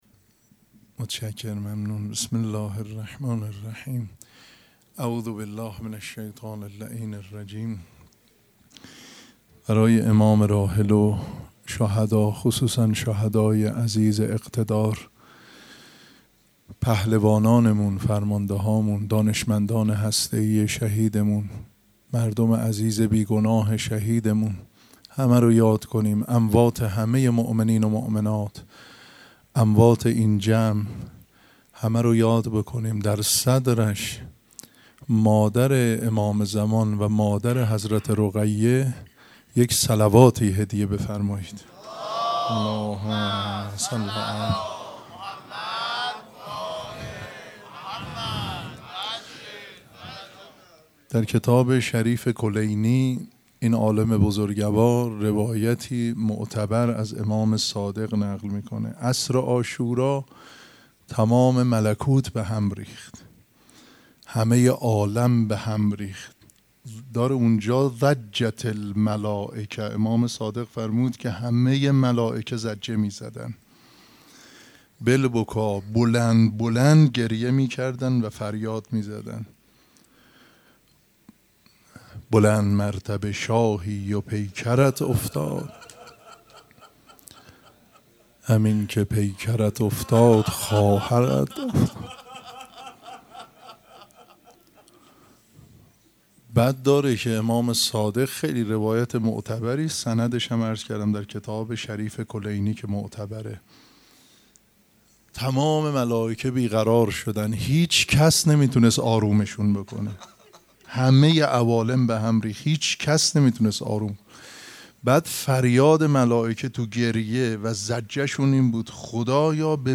سخنرانی
مراسم عزاداری شب شهادت حضرت رقیه سلام الله علیها ‌‌‌‌‌‌‌‌‌‌سه‌شنبه ۷ مرداد ۱۴۰۴ | ۴ صفر ۱۴۴۷ ‌‌‌‌‌‌‌‌‌‌‌‌‌هیئت ریحانه الحسین سلام الله علیها